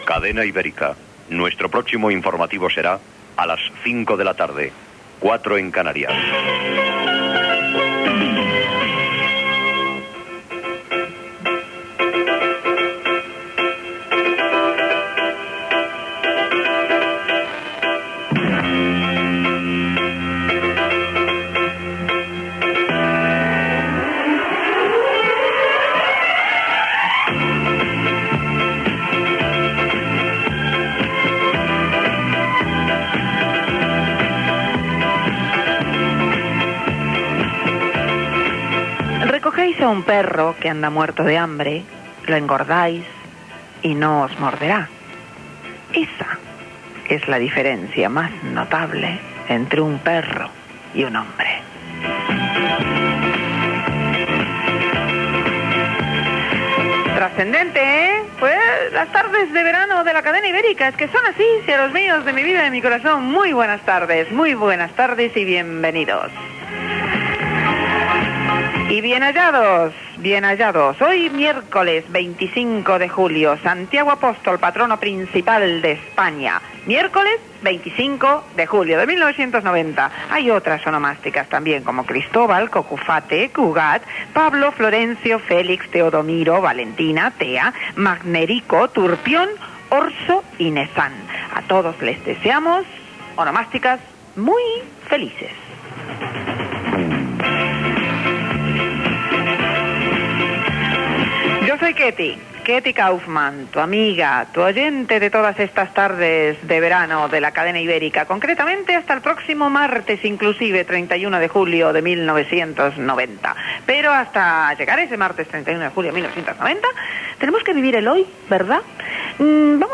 Sortida dels serveis informatius, refelxió sobre els gossos, data, santoral, trucades rebudes per al regal d'entrades
Entreteniment